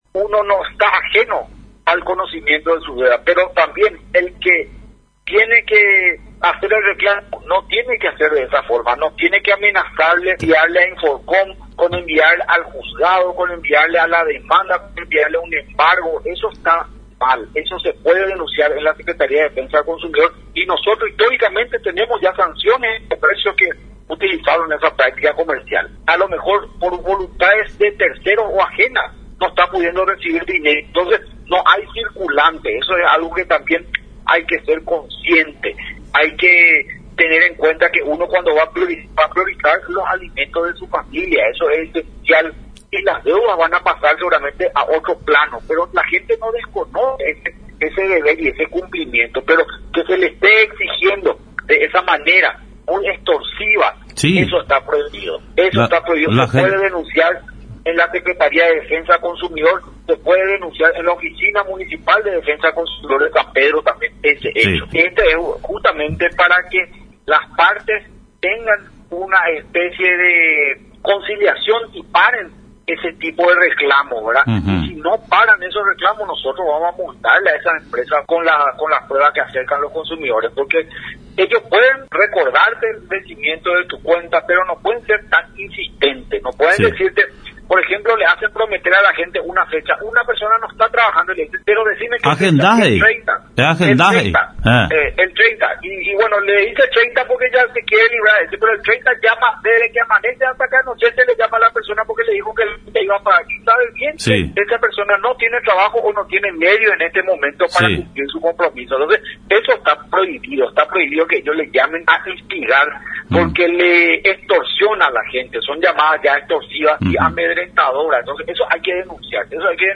El Ministro de la Secretaría de Defensa del Consumidor y el Usuario (SEDECO), Abg. Juan Manuel Estigarribia, en conversación con Radio Nacional, dio detalles acerca de las denuncias que reciben diariamente en relación a todas aquellas empresas que acosan a sus deudores. El titular de Sedeco indicó que se trata de una práctica ilegal llamar constantemente al cliente deudor condicionando o agendando una fecha tope para que se pueda acerca a abonar su deuda, o en todo caso llamar al área de recursos humanos a reclamar la deuda del trabajador de cualquier empresa.